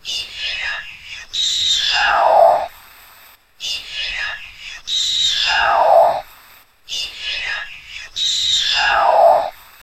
whispered voice repeating the phrase "let me out" on a loop, slowly getting clearer and more desperate.
whispered-voice-repeating-ncp5l52t.wav